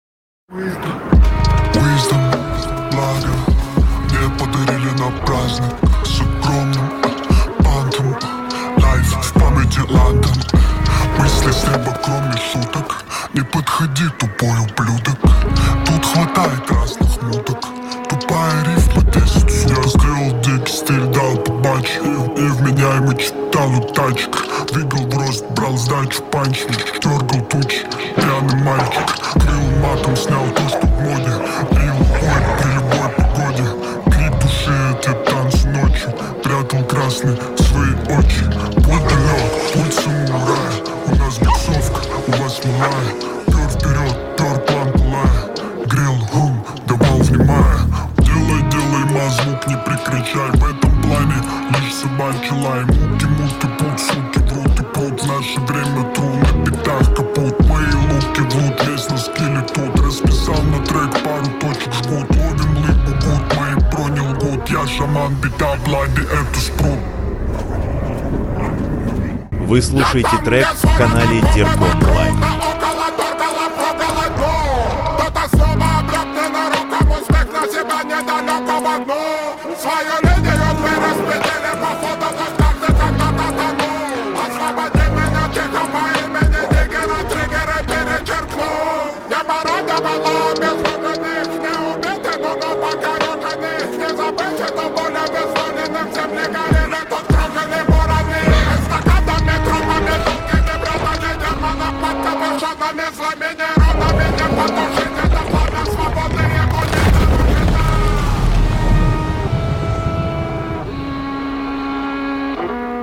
Качество: 320 kbps, stereo
Рэп, Хип-хоп, 2026